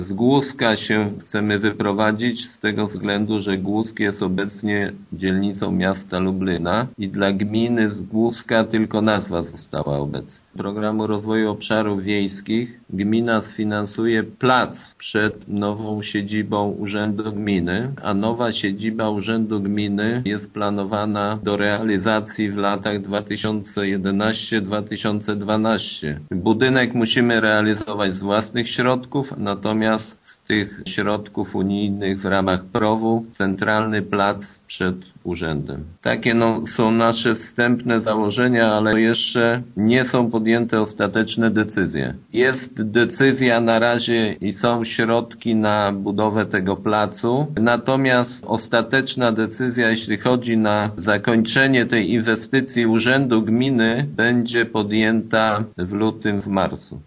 W ciągu kilku najbliższych miesięcy samorząd chce rozpocząć zagospodarowywanie centrum tej miejscowości, na co ma już zapewnione unijne dofinansowanie. Jednocześnie, z własnych środków, gmina zamierza budować nową siedzibę - wyjaśnia wójt Jacek Anasiewicz: